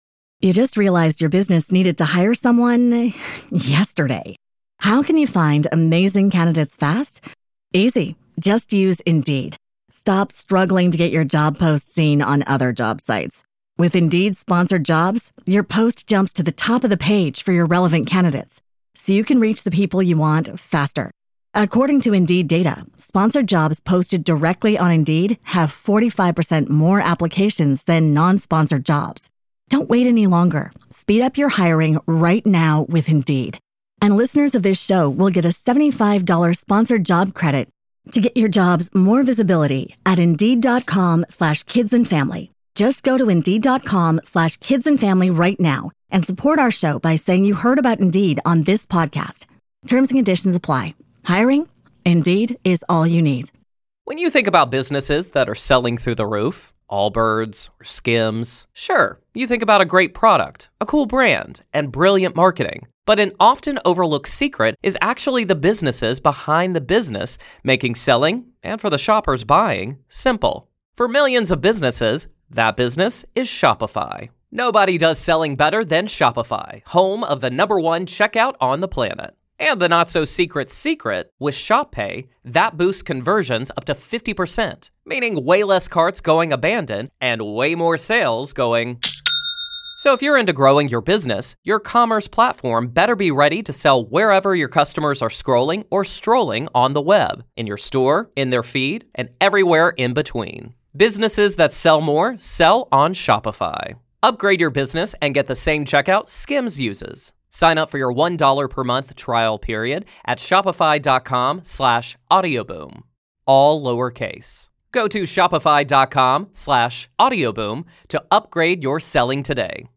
In this chilling true story, a young man recounts the relentless haunting that has plagued him and his family since childhood. From eerie music emanating from closets to phantom footsteps on ceilings, the terror begins when they move into an apartment in Alameda.